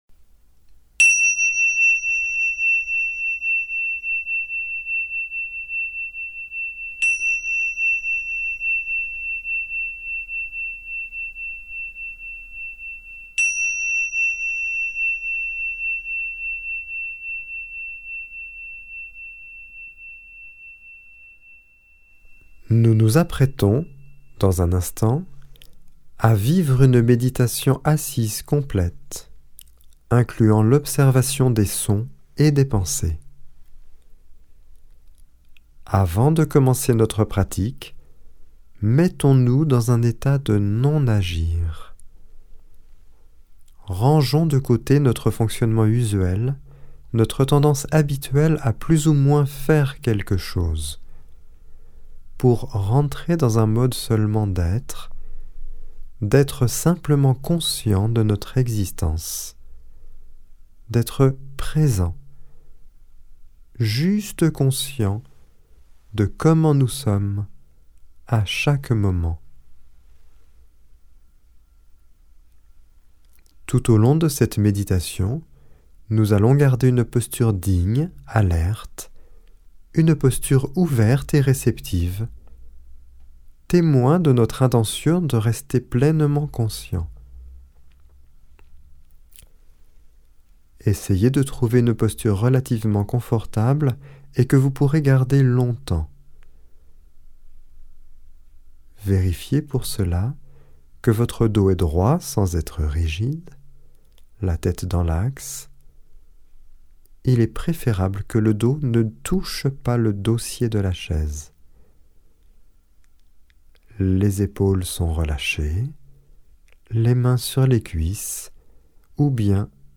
Genre : Meditative.
Etape 6 – Méditation assise complète 1